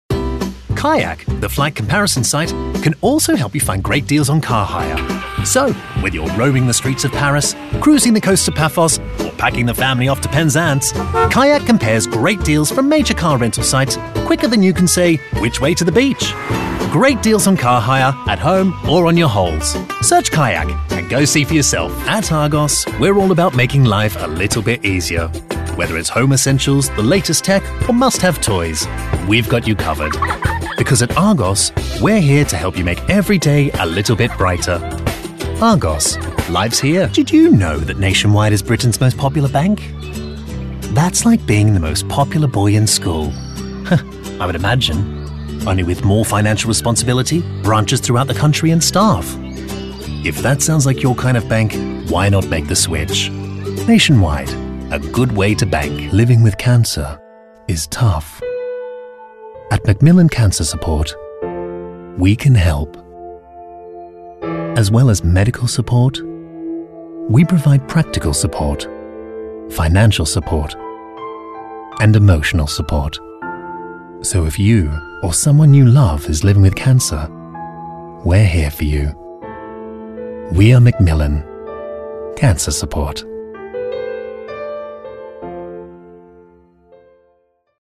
English (Australian)
English (British)
Warm
Friendly
Informative